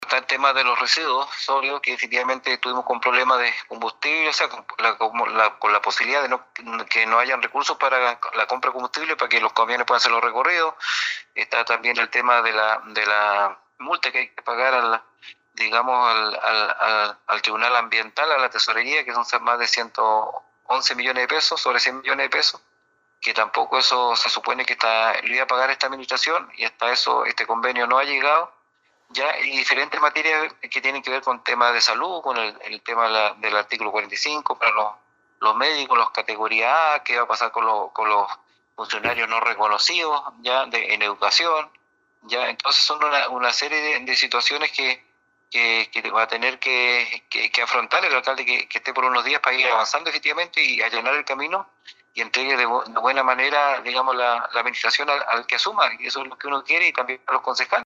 La complejidad de los temas de abordar es enorme planteó el concejal Alex Muñoz, desde los residuos sólidos domiciliarios hasta temas del ámbito educacional y de salud, de manera de aportar algo de normalidad al nuevo gobierno comunal.
18-CONCEJAL-ALEX-MUNOZ-2.mp3